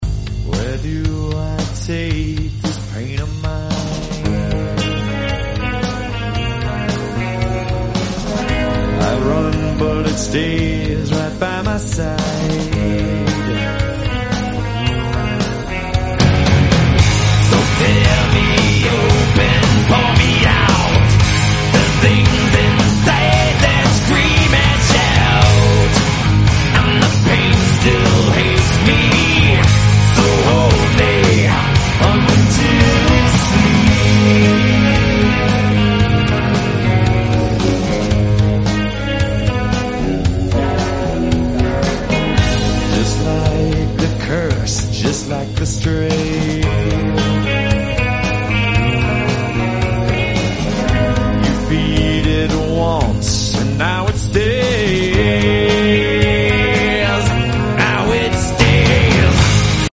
1977 South American spec Dodge sound effects free download